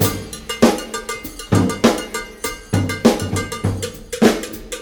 99 Bpm Drum Groove G Key.wav
Free drum groove - kick tuned to the G note. Loudest frequency: 2137Hz
99-bpm-drum-groove-g-key-uXZ.ogg